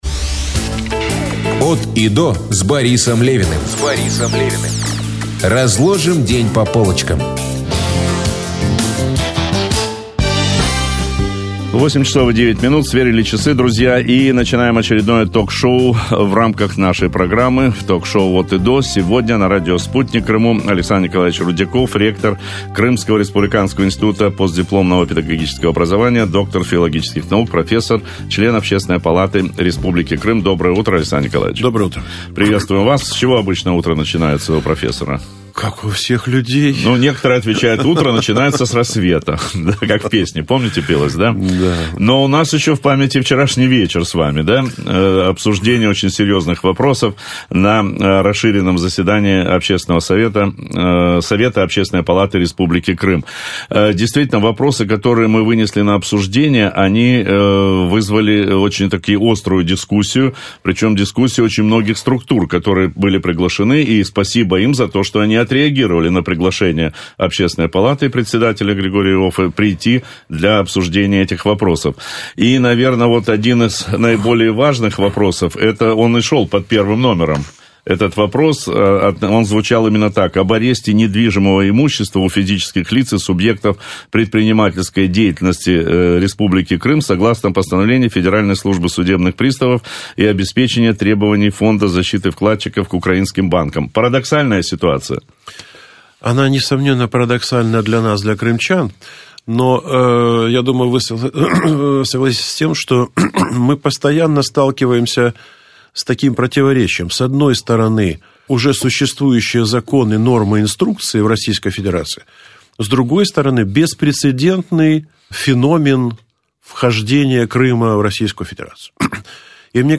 Запись трансляции.